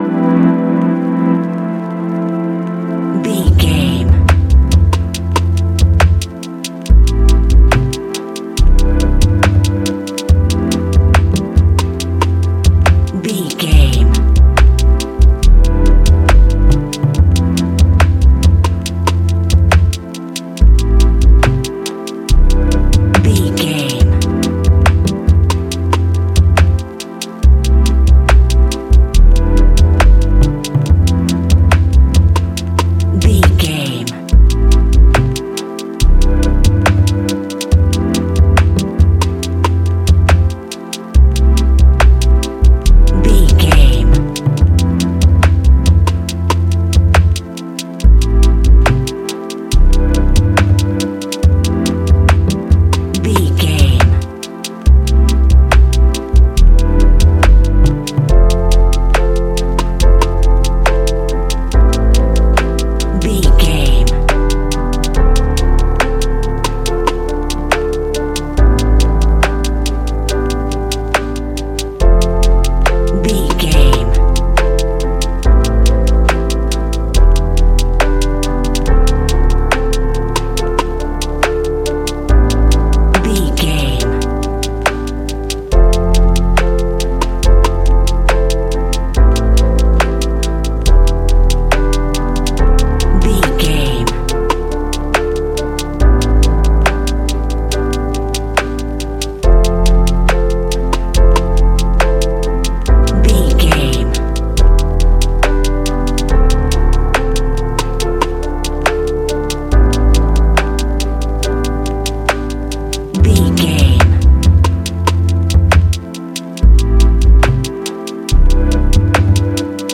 Ionian/Major
laid back
Lounge
sparse
new age
chilled electronica
ambient